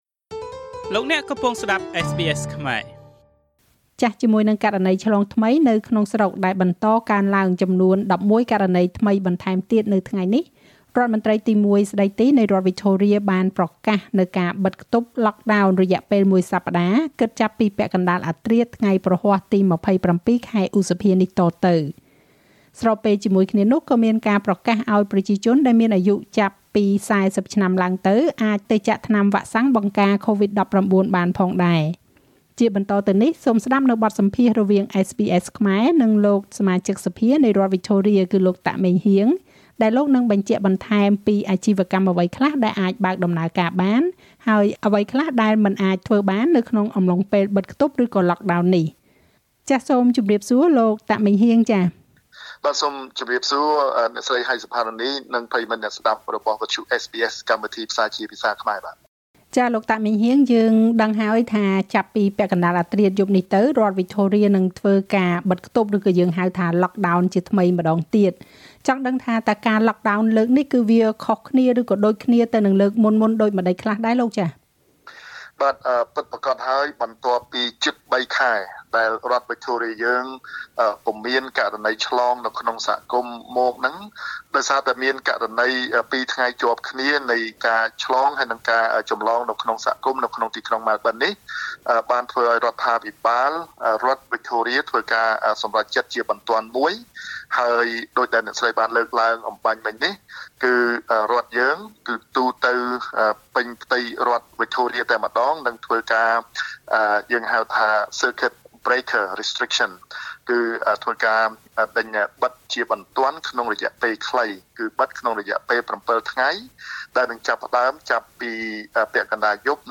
ជាបន្តទៅនេះសូមស្តាប់បទសម្ភាសន៍រវាងSBS ខ្មែរ និងលោកសមាជិកសភានៃរដ្ឋវិចថូរៀ តាក ម៉េងហ៊ាង ដែលលោកនឹងបញ្ជាក់បន្ថែមពីអាជីវកម្មអ្វីខ្លះអាចបើកដំណើរការបាន អ្វីខ្លះមិនអាចធ្វើបាននៅក្នុងអំឡុងពេលបិទខ្ទប់ ឬ lockdown នេះ។